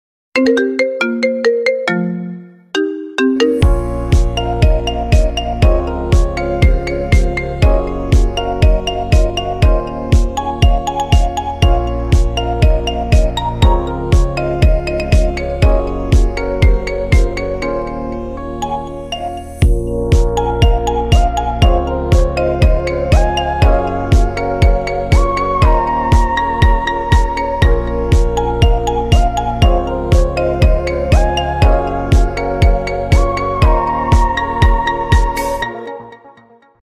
Kategoria Marimba Remix